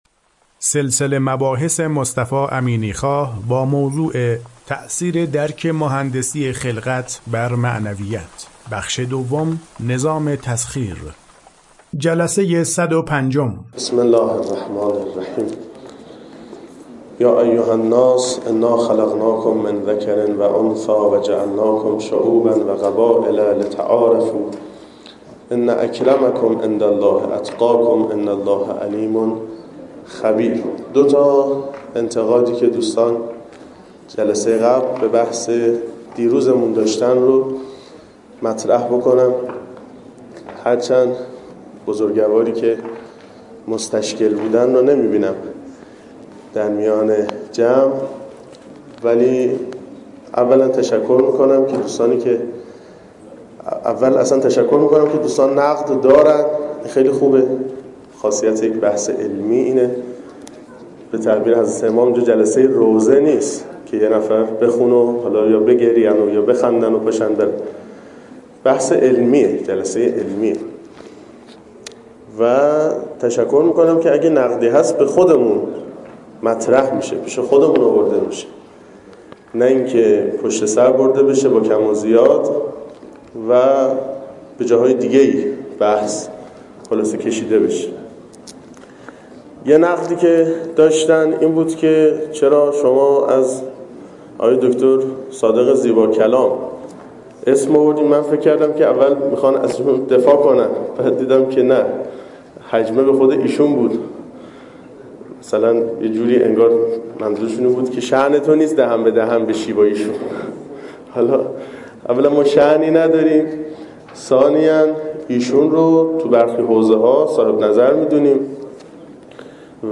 سلسله مباحث مهندسی خلقت که در دانشکده مهندسی دانشگاه فردوسی ارائه شده در چند بخش پیگیری می شود که شمای کلی آن بدین شرح است: